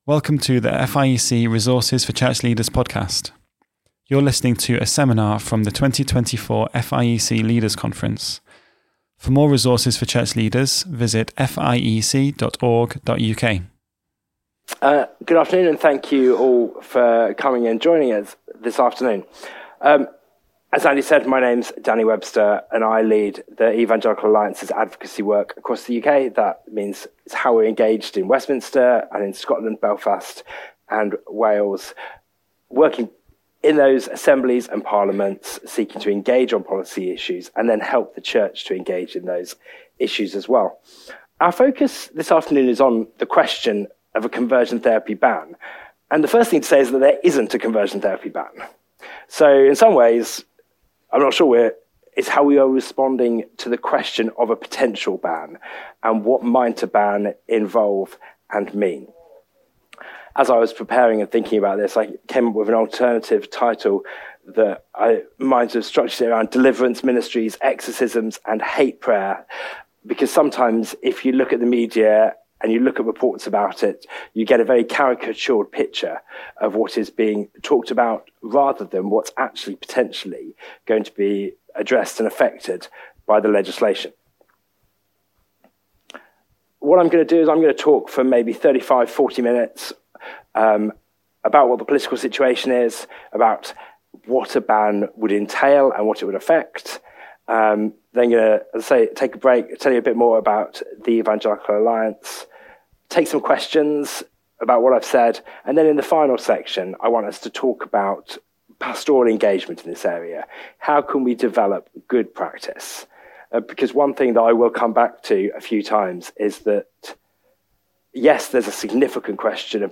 What are the dangers in the policy area of 'conversion therapy' and how should churches respond to the challenge? From the 2024 Leaders' Conference.